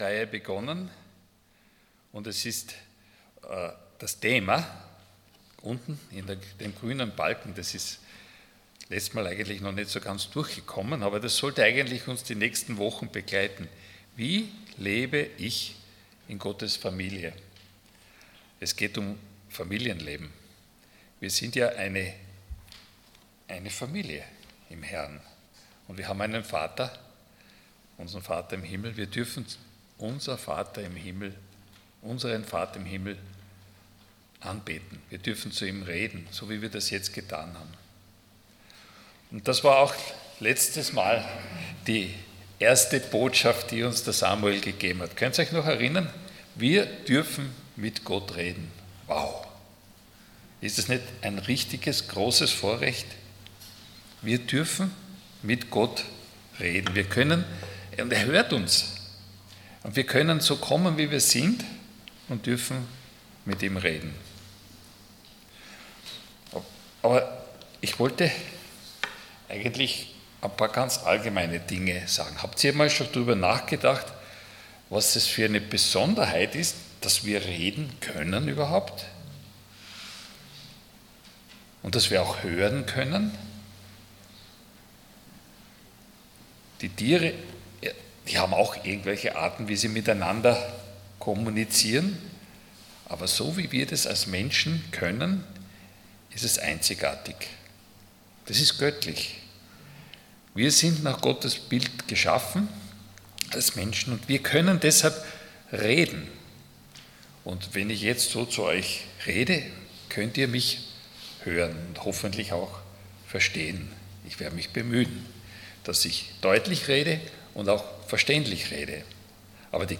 Dienstart: Sonntag Morgen Wie höre ich meinen Vater im Himmel? Themen: Gebet , Kommunikation « Wie rede ich mit meinem Vater im Himmel?